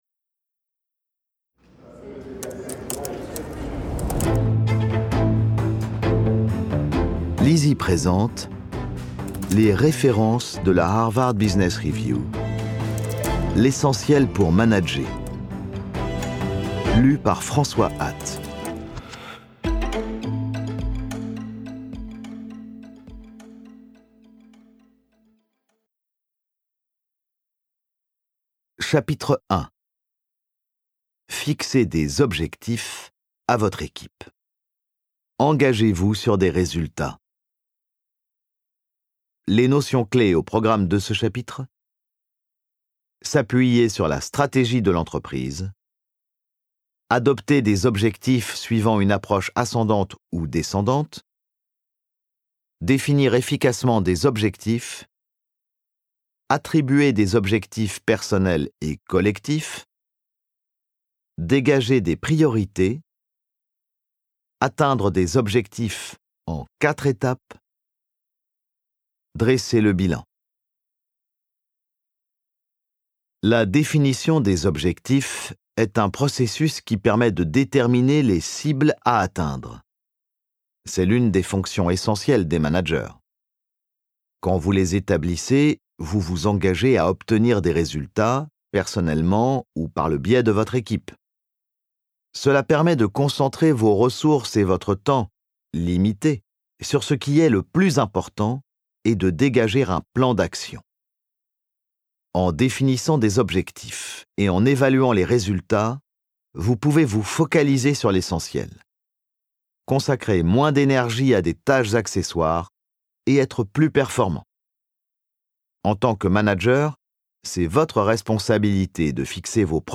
Grâce à ce livre audio concret et opérationnel, v...